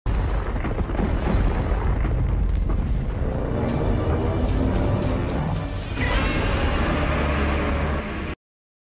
Evolved Godzilla awakening from ice